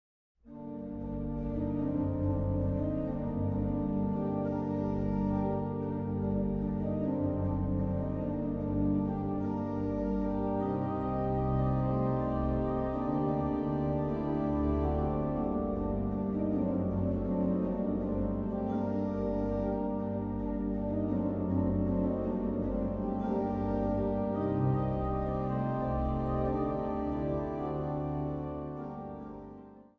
Zang | Jongerenkoor